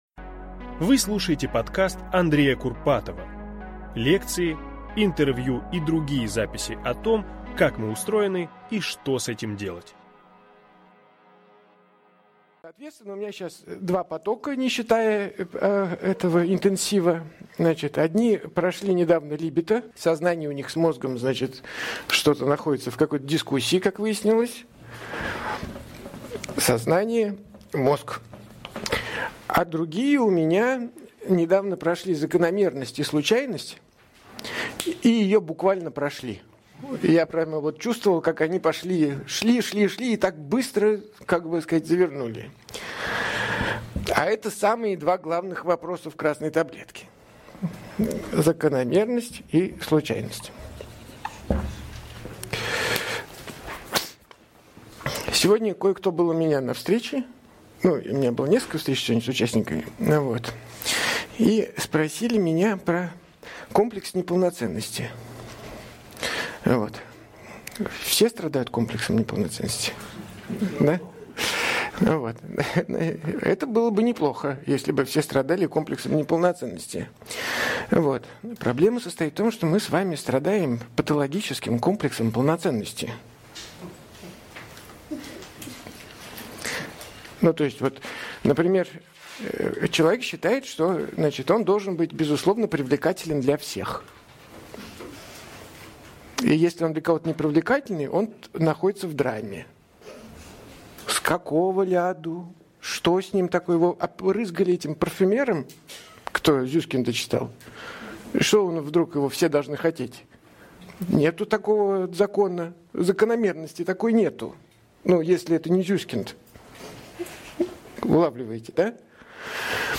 Аудиокнига Две вещи, которые заставят ваш мозг работать | Библиотека аудиокниг